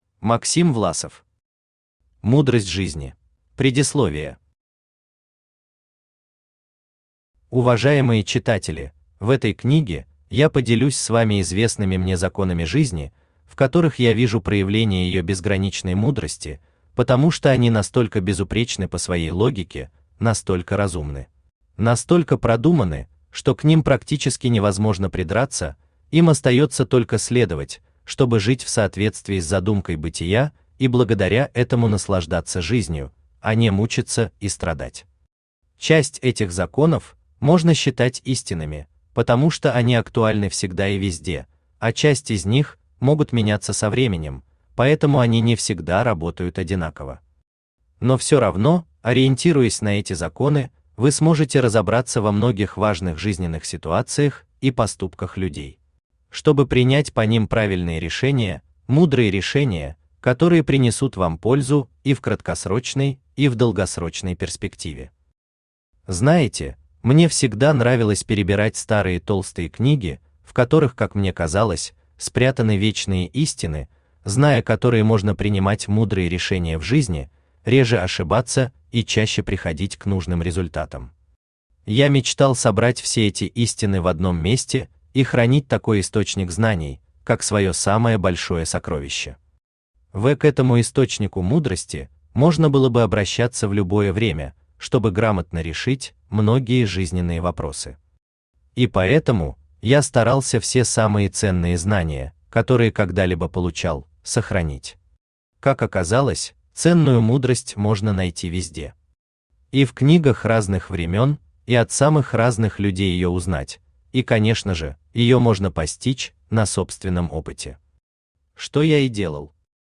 Аудиокнига Мудрость жизни | Библиотека аудиокниг